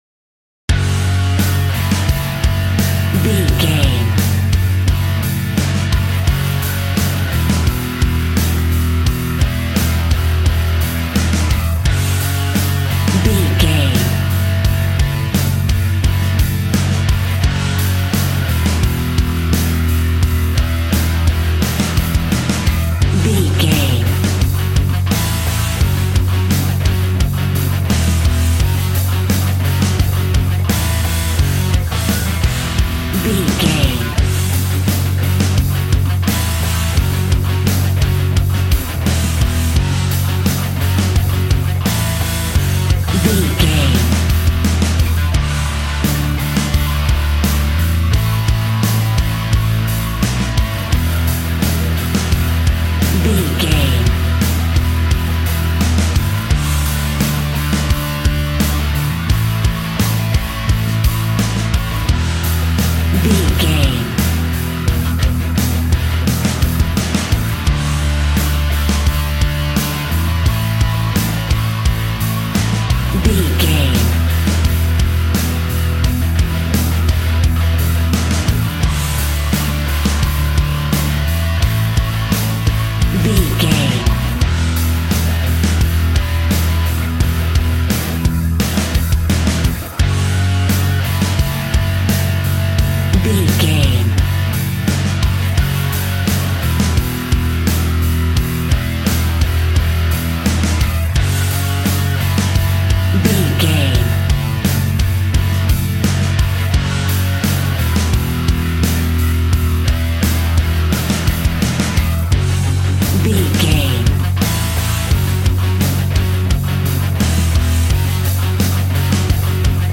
Ionian/Major
angry
aggressive
electric guitar
drums
bass guitar